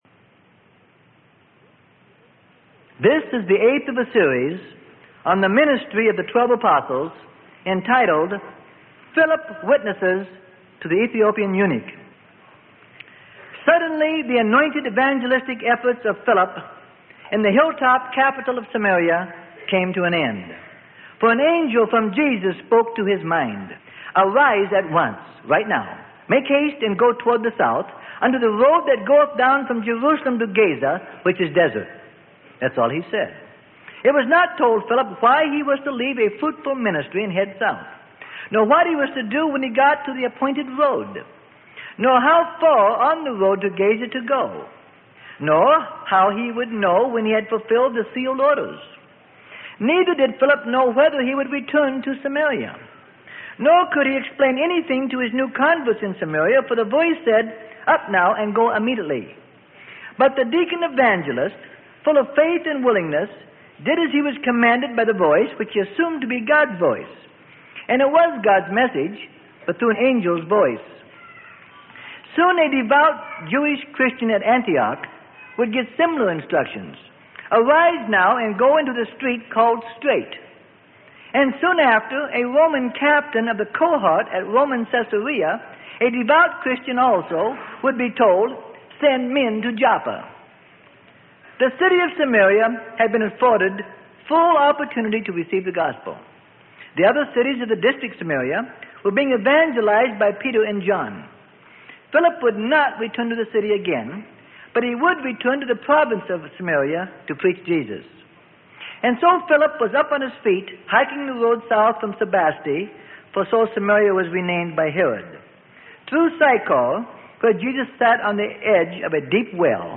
Sermon: The Acts Of The Apostles - Part 08 Of 13.